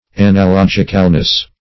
Analogicalness \An`a*log"ic*al*ness\, n.